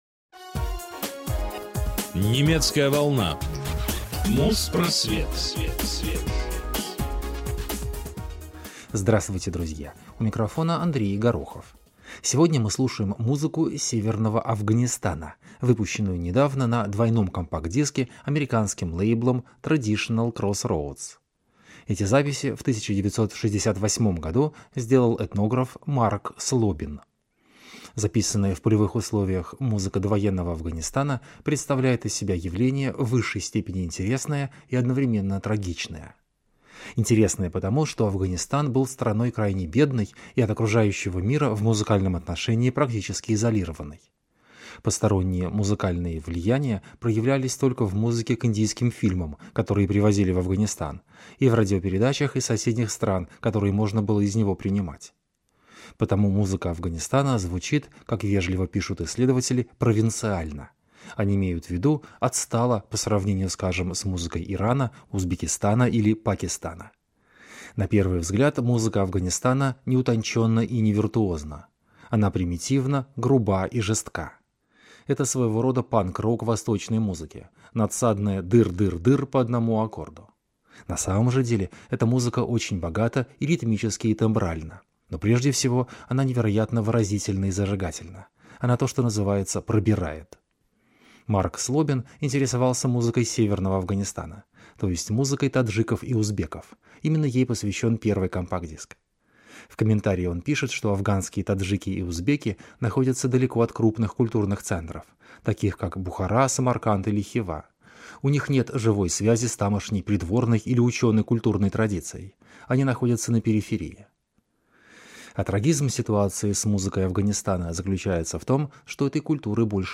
Довоенная музыка северного Афганистана. Записанная в полевых условиях музыка 1958 года.